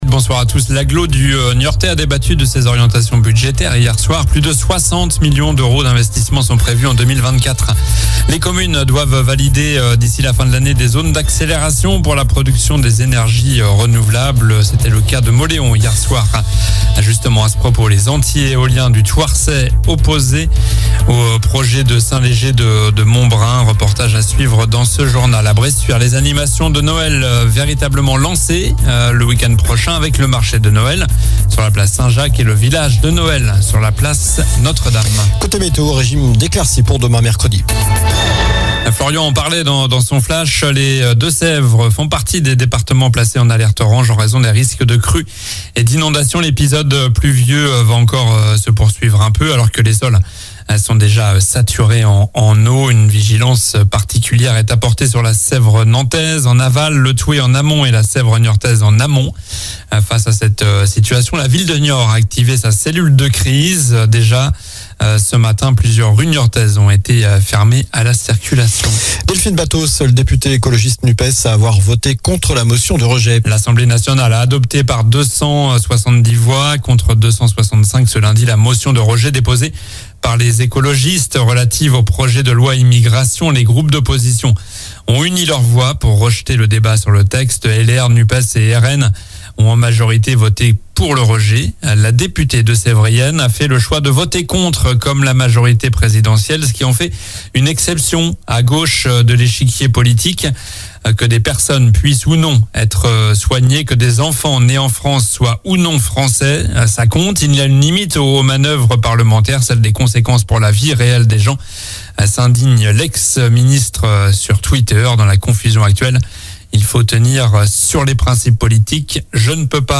Journal du mardi 12 Decembre (soir)